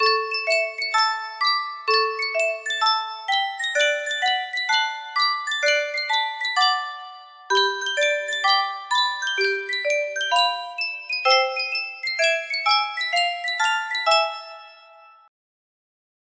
Sankyo Music Box - Ballin' the Jack K+ music box melody
Full range 60